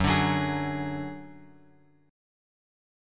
F#sus2#5 chord {x x 4 1 3 2} chord